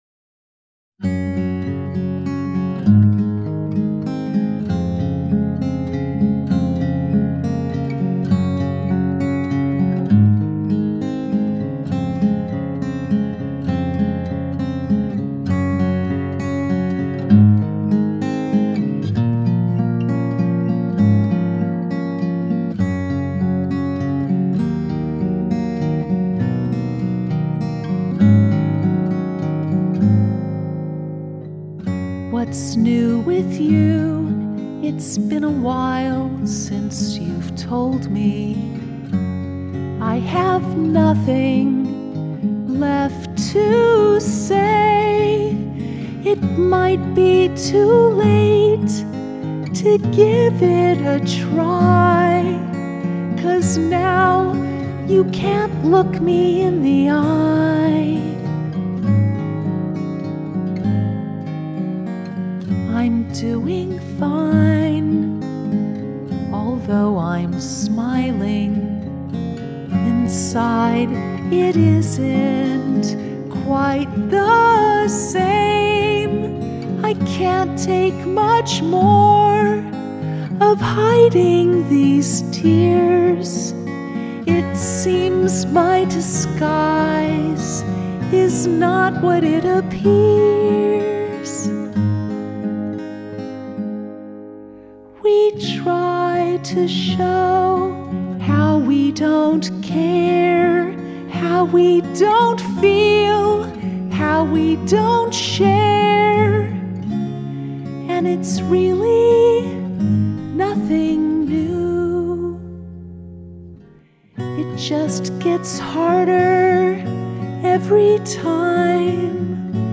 Acoustic
The verse and chorus had two distinctly different rhythms.